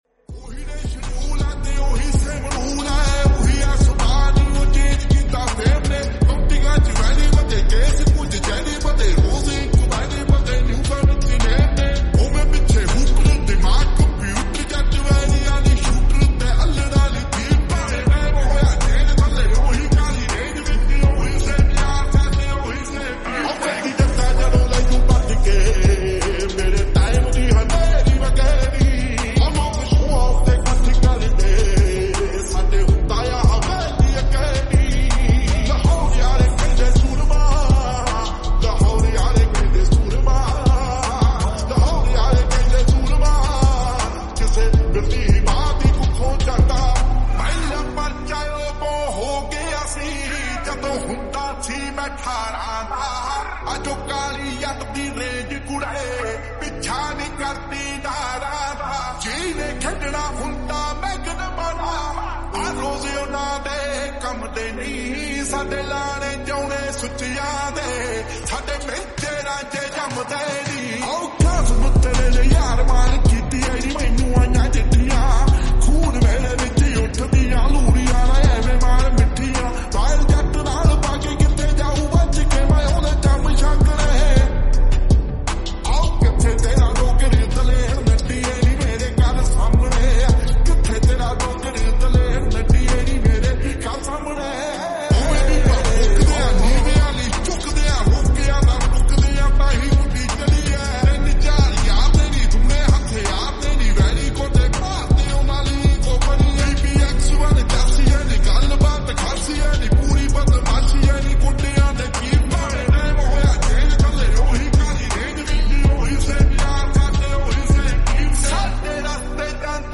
𝑴𝑬𝑺𝑯𝑼𝑷 𝑺𝑶𝑵𝑮
𝑺𝑶𝑳𝑽𝑬𝑫 𝑹𝑬𝑽𝑬𝑹𝑩